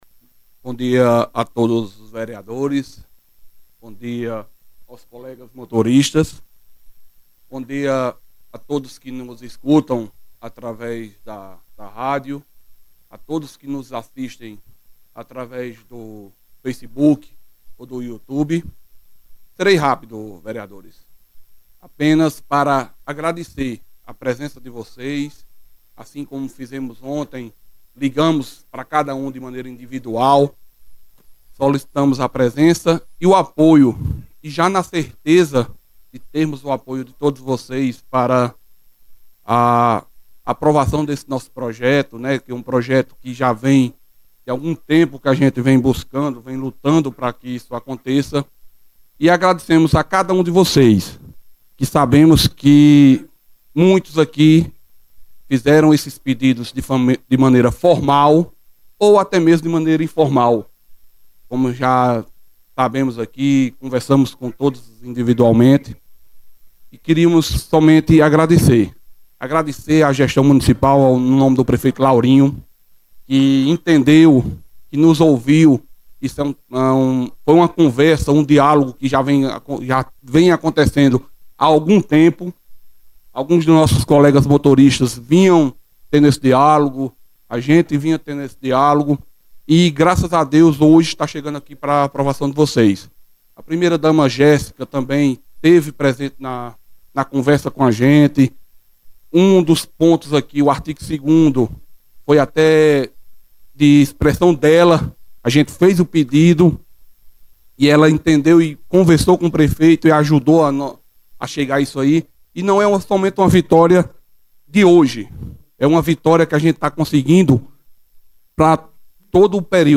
A presença dos motoristas no plenário reforçou a importância histórica da pauta, que vinha sendo debatida há vários anos com a administração municipal.